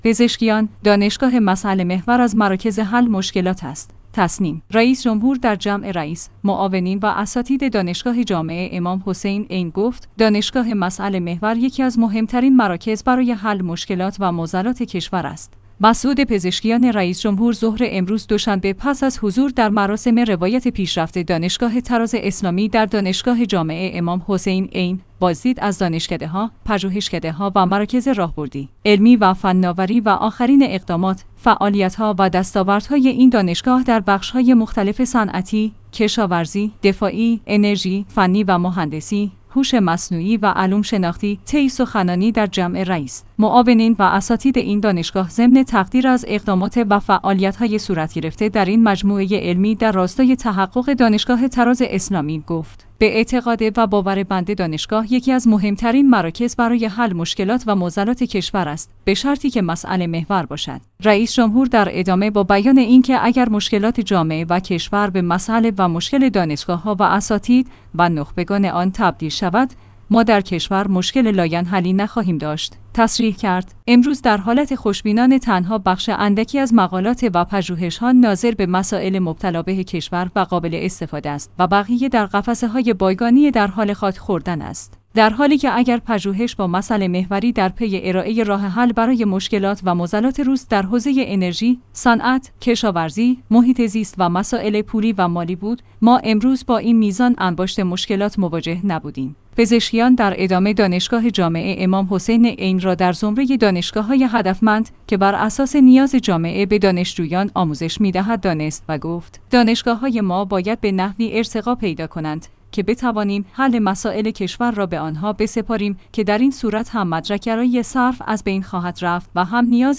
تسنیم/ رییس جمهور در جمع رئیس، معاونین و اساتید دانشگاه جامع امام حسین(ع) گفت: دانشگاه مسئله‌محور یکی از مهمترین مراکز برای حل مشکلات و معضلات کشور است.